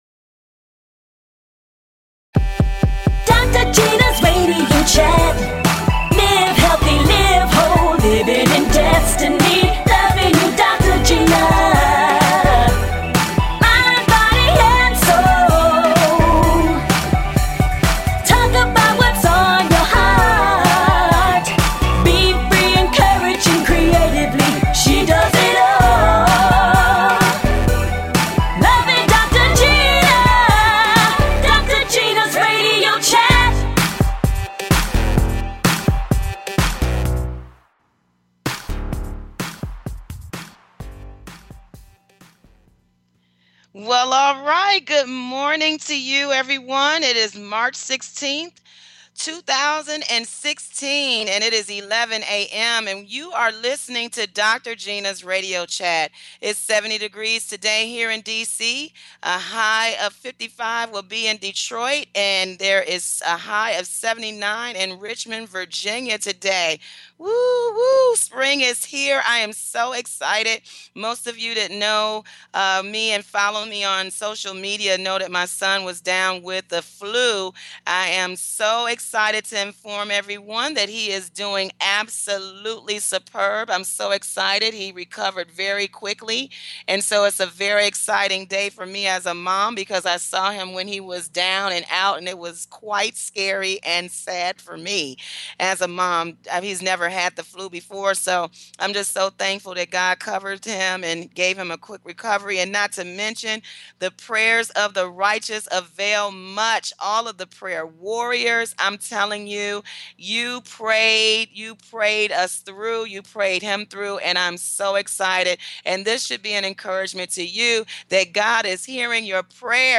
And full of laughter!
A talk show of encouragement.